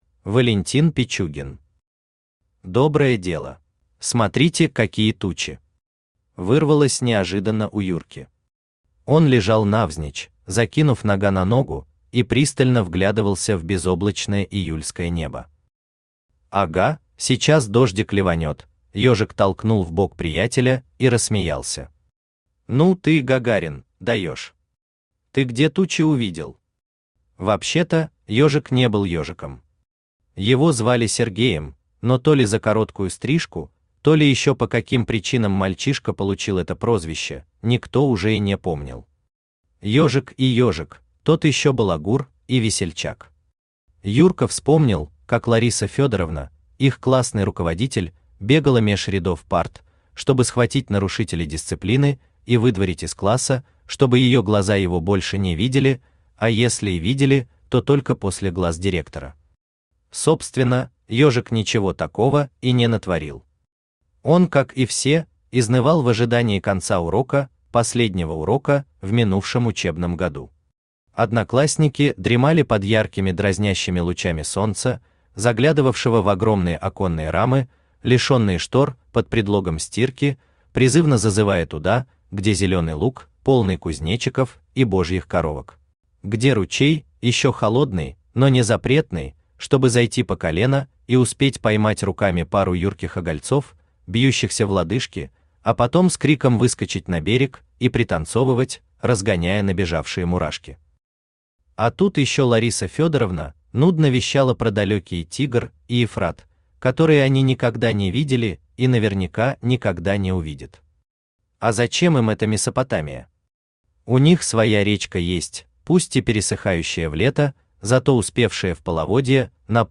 Аудиокнига Доброе дело | Библиотека аудиокниг
Aудиокнига Доброе дело Автор Валентин Николаевич Пичугин Читает аудиокнигу Авточтец ЛитРес.